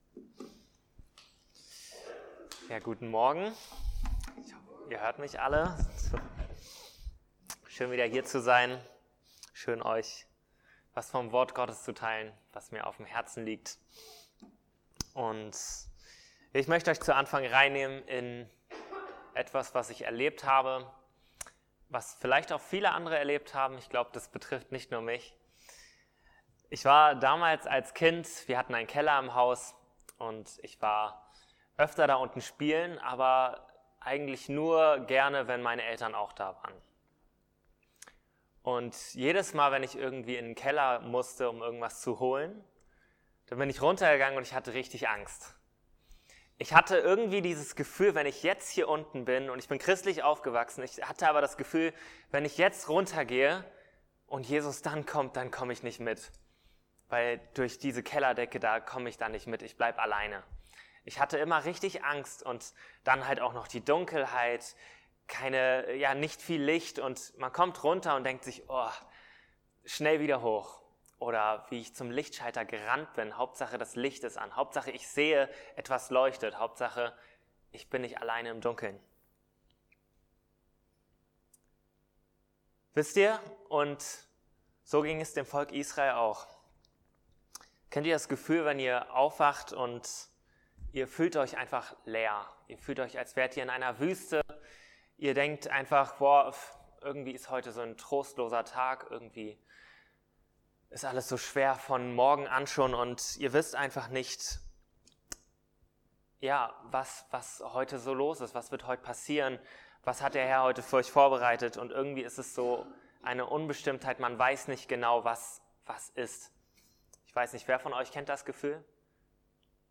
Mose 14,21 Dienstart: Predigt So wie Gott im Zelt der Begegnung unter dem Volk lebte, will Jesus auch in unserem Herzen wohnen.